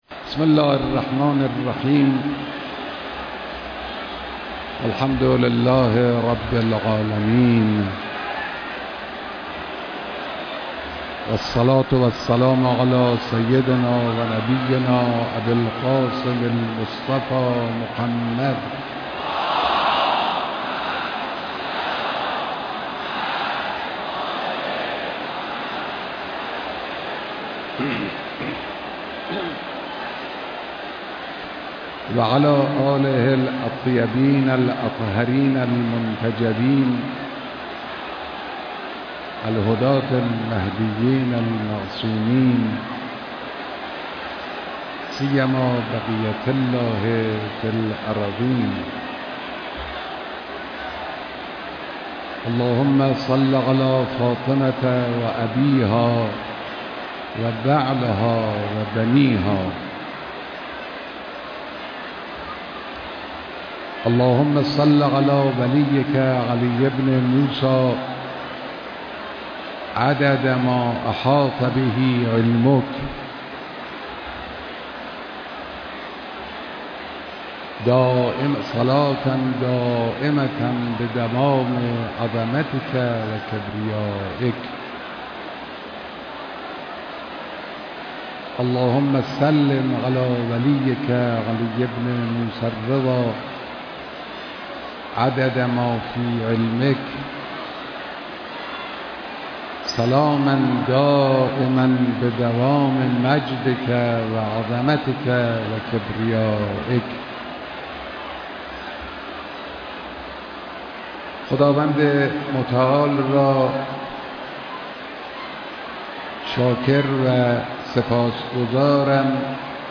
بیانات در اجتماع عظیم زائران و مجاوران حرم رضوی (ع)